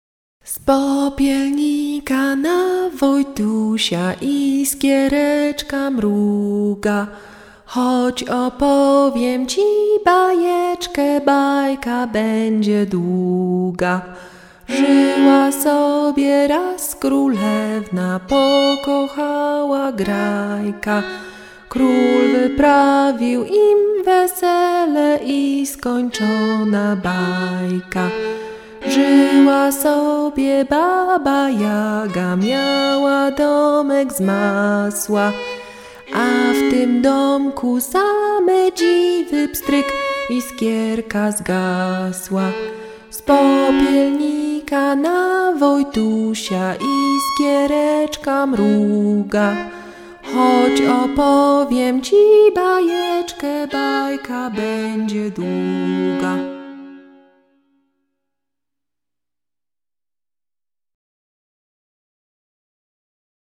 Польская колыбельная песня - слушать онлайн
Красивые спокойные песни народов Польши для быстрого засыпания детей.